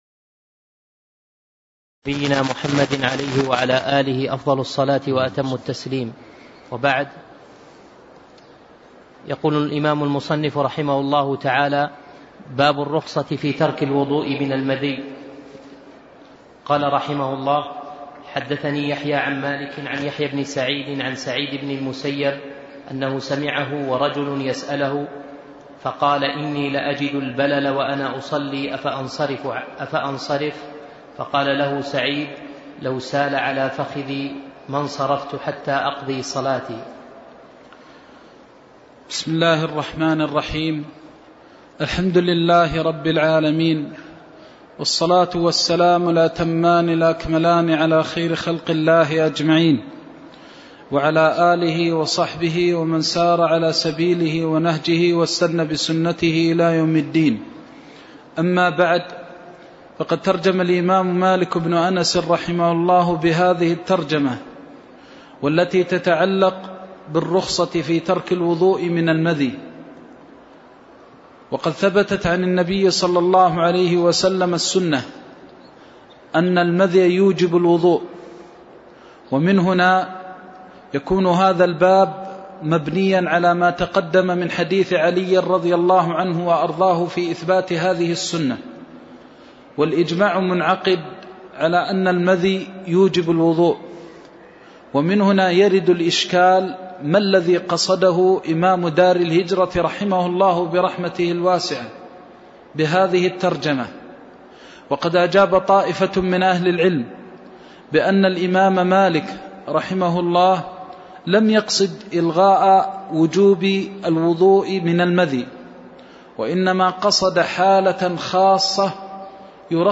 الدرس الرابع عشر من قول المصنف رحمه الله :باب الرخصة في ترك الوضوء من المذي إلى قول المصنف رحمه الله :باب الوضوء من مس الفرج